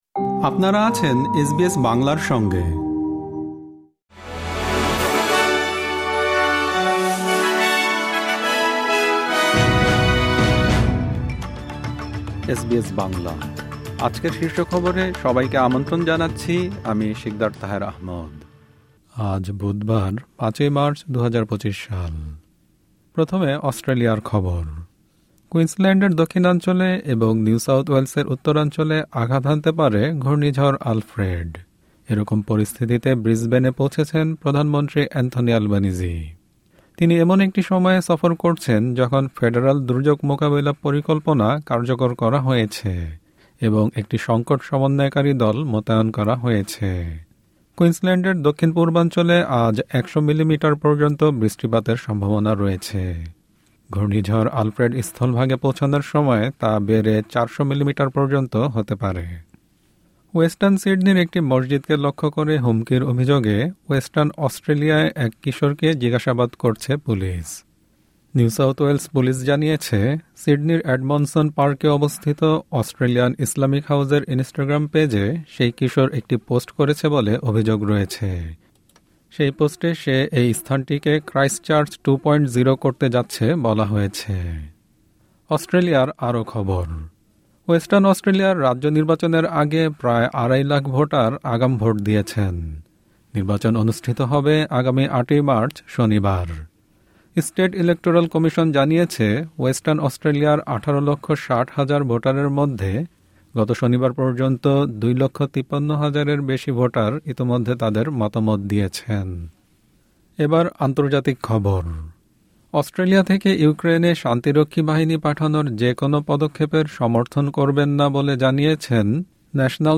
এসবিএস বাংলা শীর্ষ খবর: ৫ মার্চ, ২০২৫